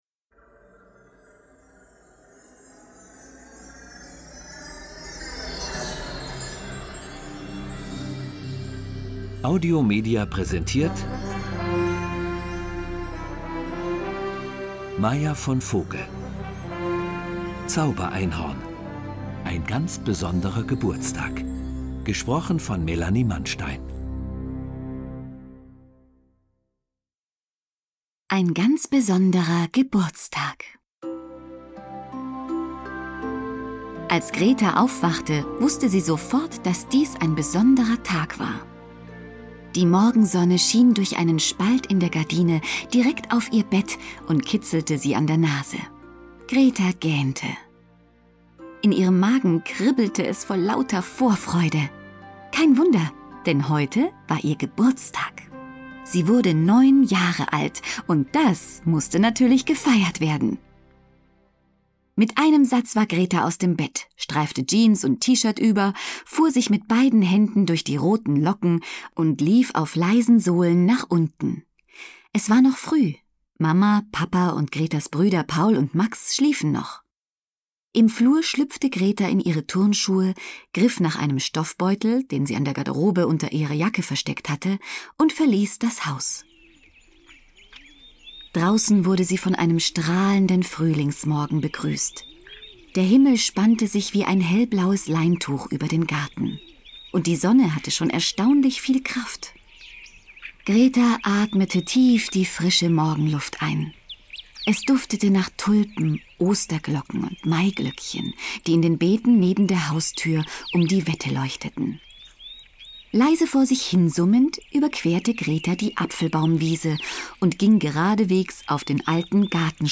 Schlagworte Einhorn • Einhorn / Einhörner; Kinder-/Jugendliteratur • Einhorn; Kinder-/Jugendliteratur • Hörbuch; Lesung für Kinder/Jugendliche • Pony • Sternchen • Zaubereinhorn